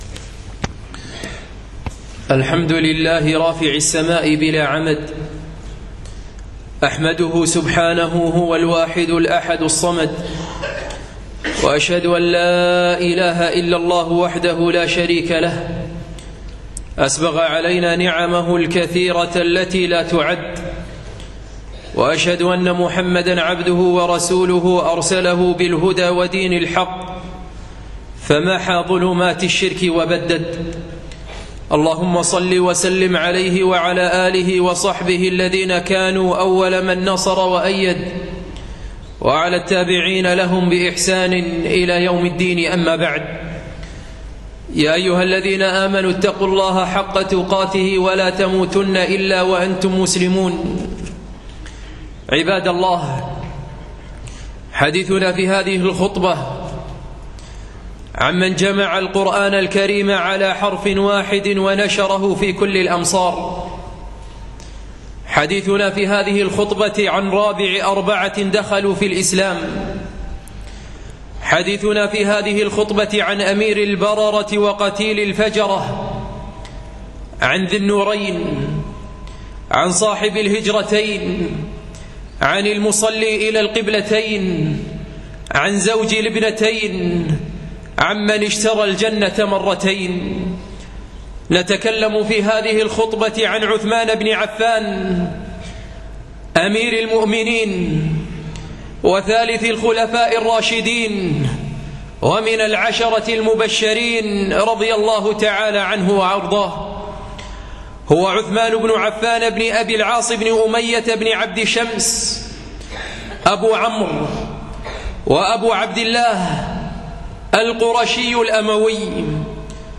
الجمعة 1 7 1437 الموافق 8 4 2016 مسجد العلاء بن عقبة الفردوس